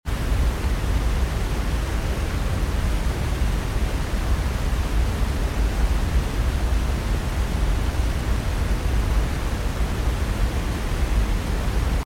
Mp3 Sound Effect Flying high above the treetops, the dense canopy opens up to reveal a magnificent waterfall cascading powerfully over the cliff’s edge.
The sound, the scale, and the serenity all blend together in this moment—reminding us just how humbling and peaceful it is to witness nature at its wildest.